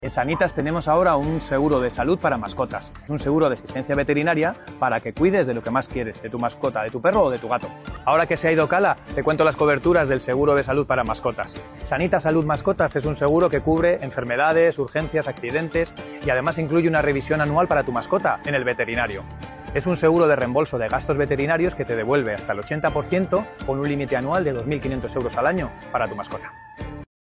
Así suenan algunas de nuestras voces profesionales para vídeos: